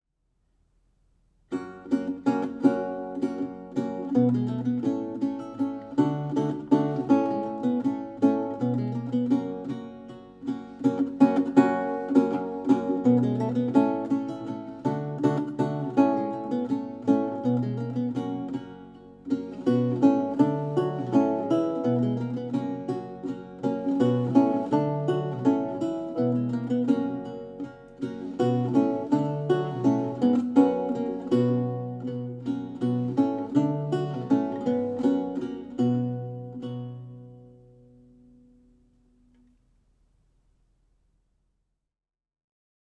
Audio recording of a lute piece
16th century lute music piece